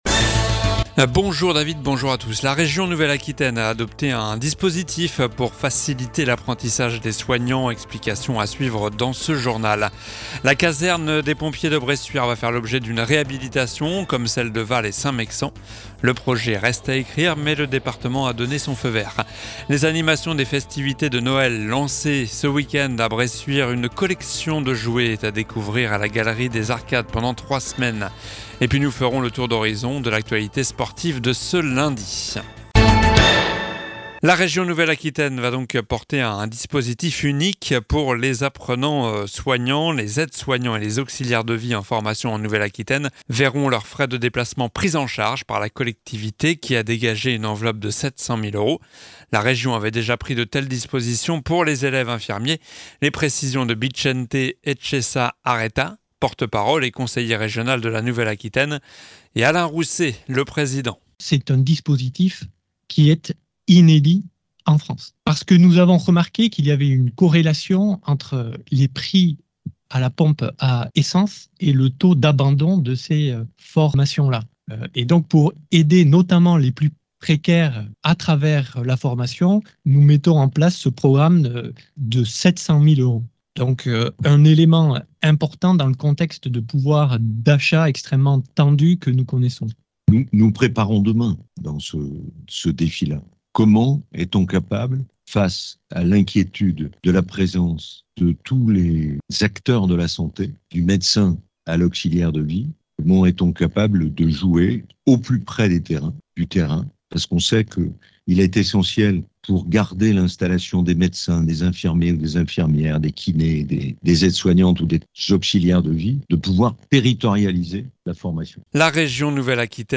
Journal du lundi 18 décembre (lundi)